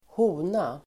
Ladda ner uttalet
hona substantiv, female Uttal: [²h'o:na] Böjningar: honan, honor Definition: djur av kvinnligt kön (an animal of the female sex) Sammansättningar: hon|kön (female sex), fågelhona (hen) female substantiv, hona [inom zoologi]